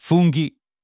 GH = Always hard "G" sound (the H keeps it hard)
mushrooms FOON-gee